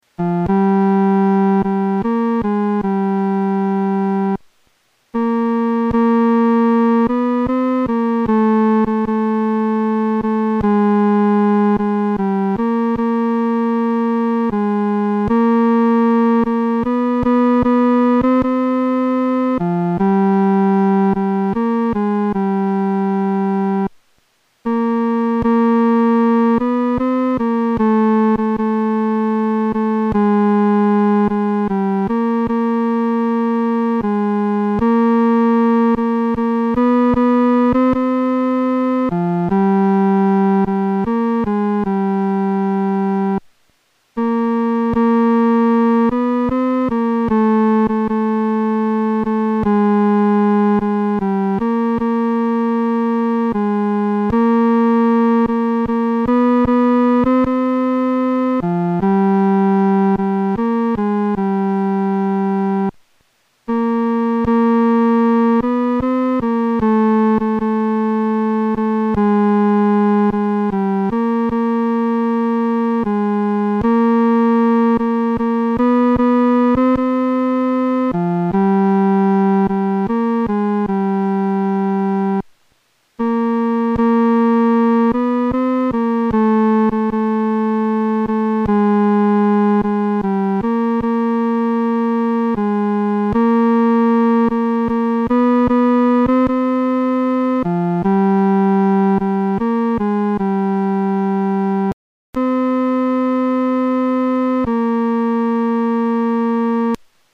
伴奏
男高